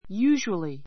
usually 小 A1 júːʒuəli ユ ージュアり 副詞 たいてい , いつも（は）, ふつう ✓ POINT always （いつも）ではないが, often （しばしば）よりは多い.